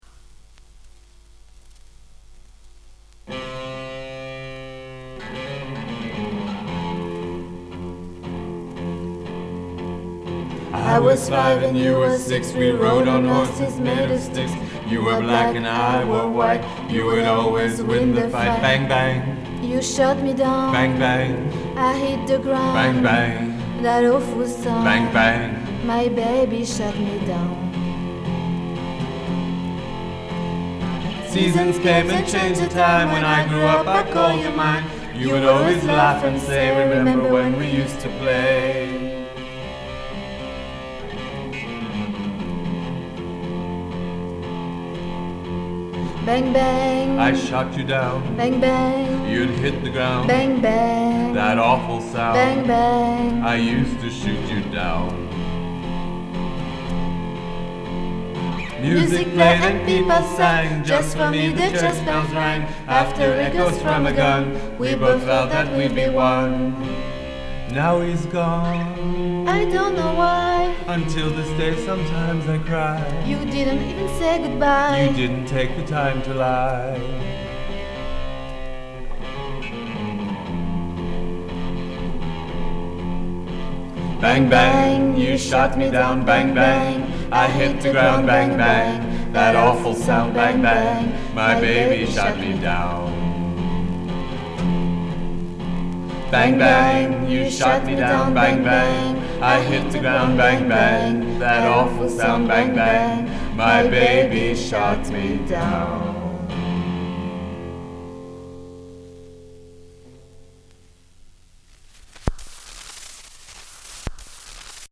Chant
Guitare